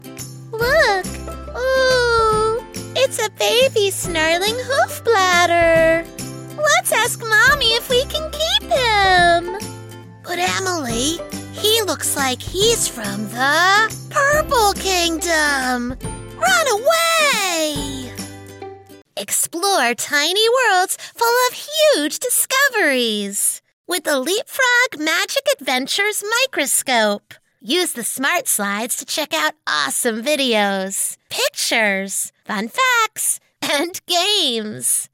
Female
Child Voice Demos
Kids Characters
Words that describe my voice are animated, friendly, dramatic.